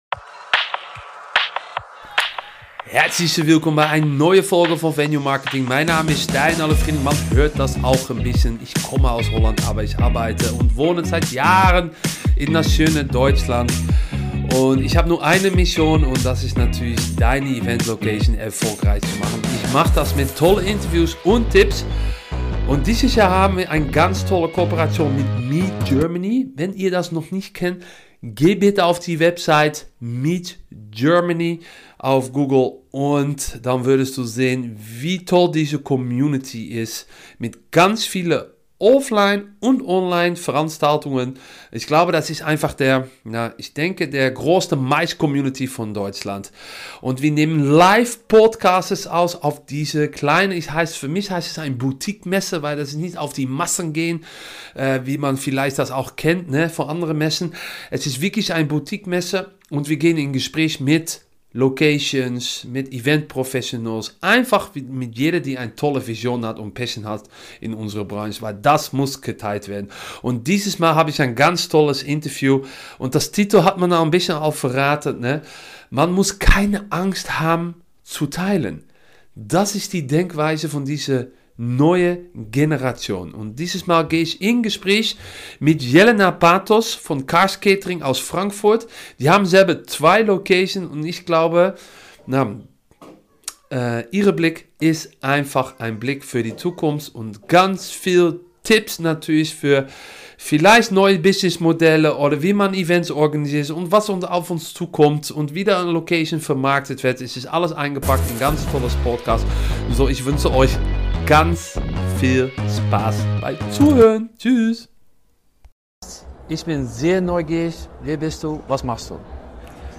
B2B Marketingpodcast für Eventlocations & Hotels Podcast